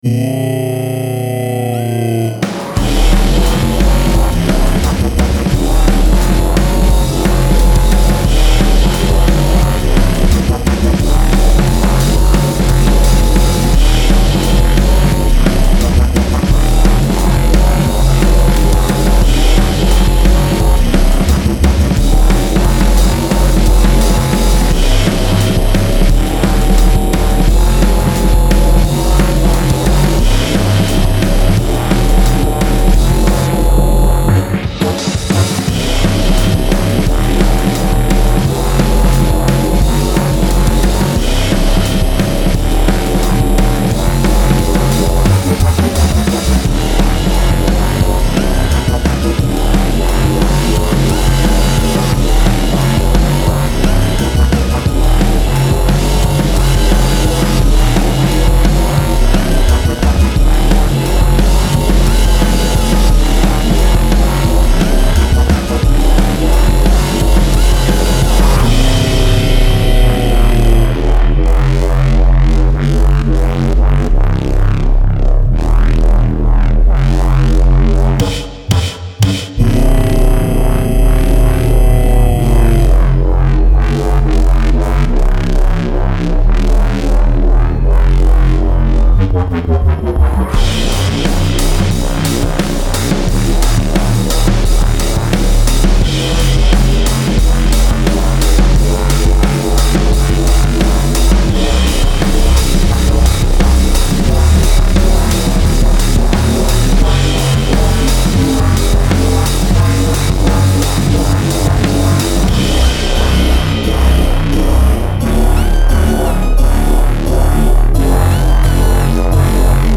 Style Style EDM/Electronic
Mood Mood Driving
Featured Featured Bass, Drums, Synth +1 more
BPM BPM 175